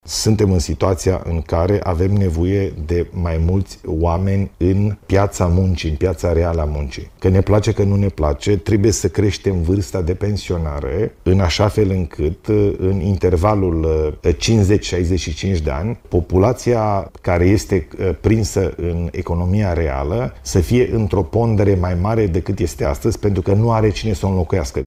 Ilie Bolojan a declarat luni seară, într-un interviu la postul public de televiziune, că vârsta de pensionare la toate categoriile trebuie crescută.